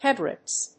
音節Hé・bra・ist 発音記号・読み方
/‐ɪst(米国英語)/